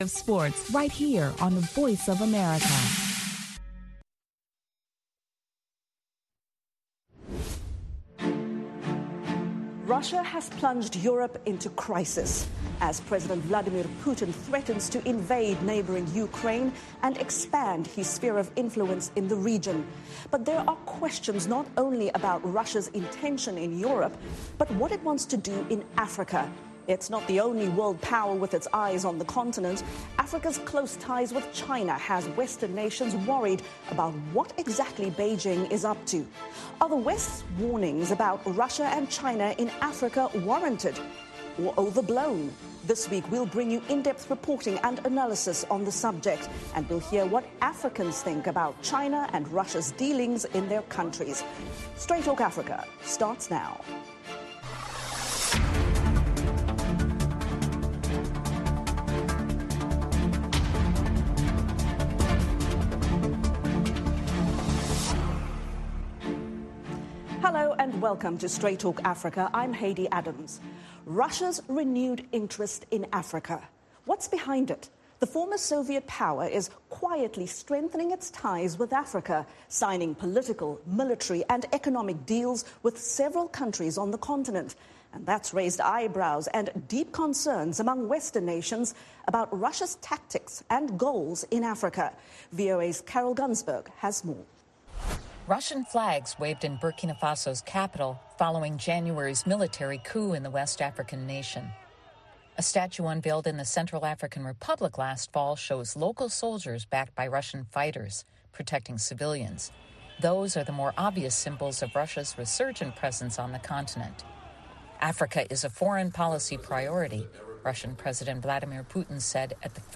Russia and China in Africa [simulcast]